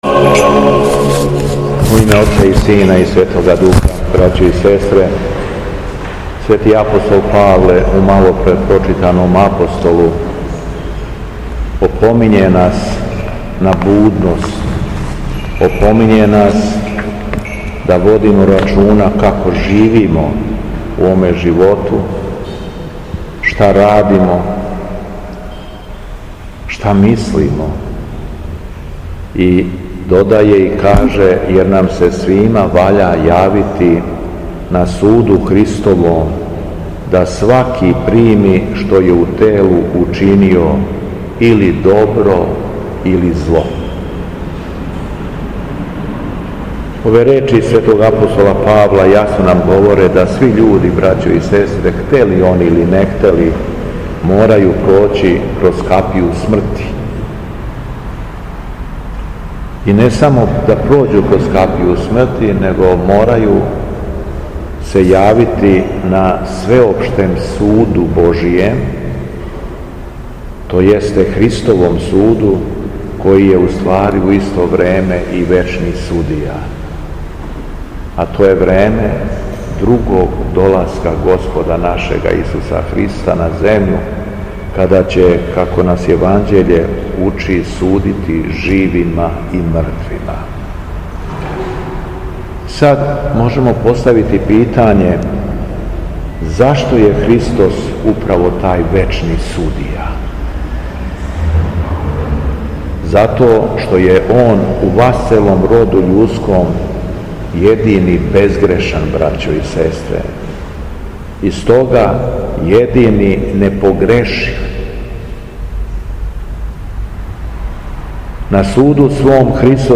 Беседа Његовог Високопреосвештенства Митрополита шумадијског г. Јована
У понедељак дванаести по Духовима, када наша Света Црква прославља свете мученике Фотија, Аникиту и друге, Његово Високопреосвештенство Митрополит шумадијски Господин Јован, служио је свету архијереску литургију у храму Светога Саве у крагујевачком насељу Аеродром.